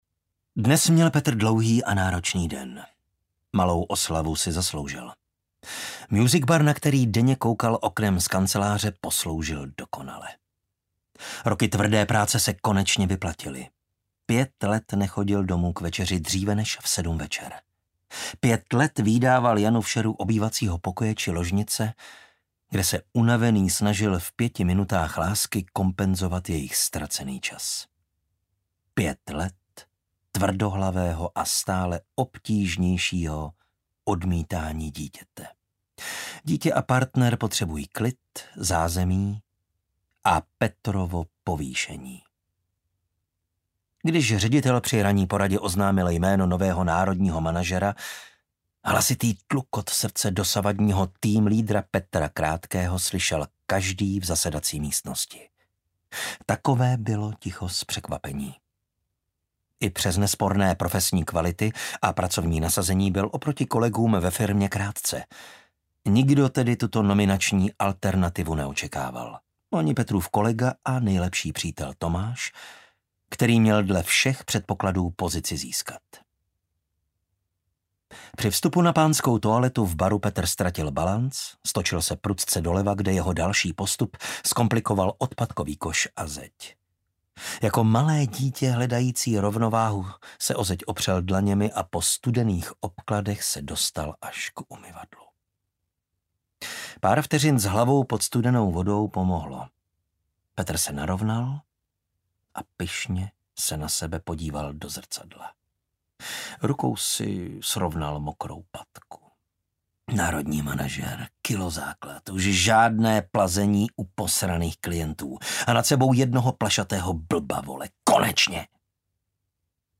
Proměna audiokniha
Ukázka z knihy